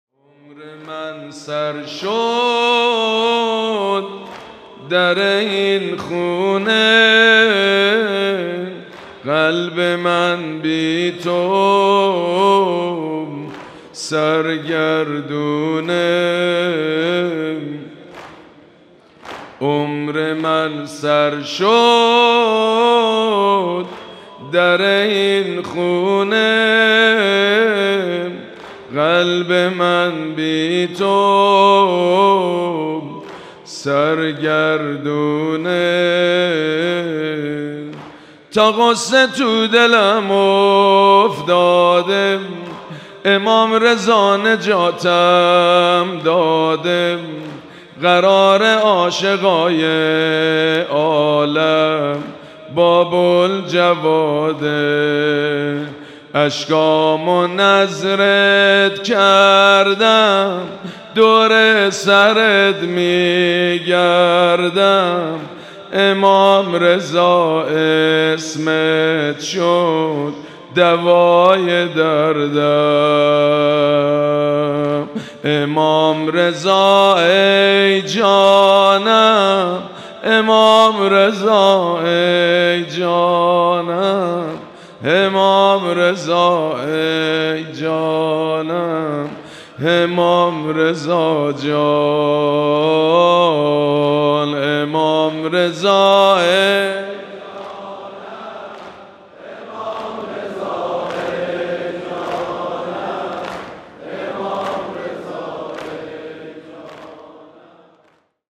مراسم عزاداری ظهر سی‌ام ماه صفر
حسینیه امام خمینی (ره)
مداح
حاج سید مجید بنی فاطمه